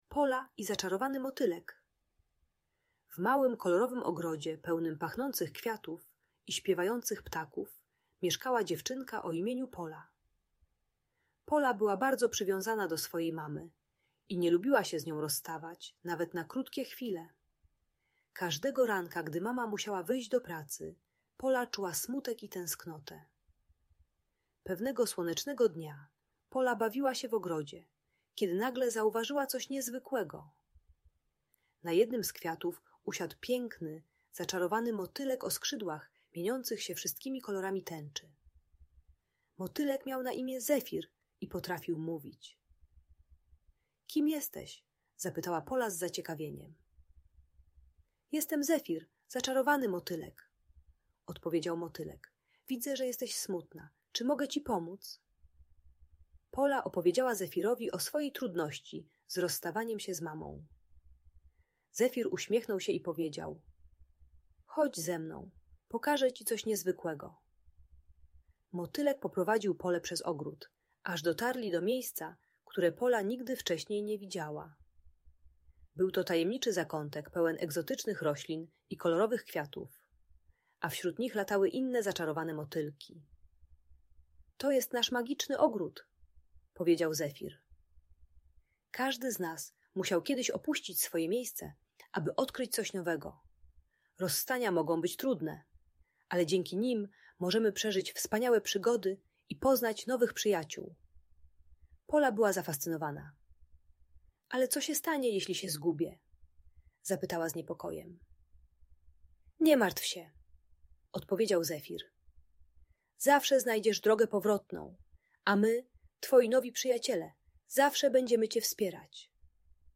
Pola i Zaczarowany Motylek - Audiobajka